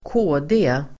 Ladda ner uttalet
KD förkortning, CDS; Christian Democratic Party Uttal: [²kå:de:] Definition: Kristdemokraterna Förklaring: Ett av de politiska partier som är representerade i den svenska riksdagen.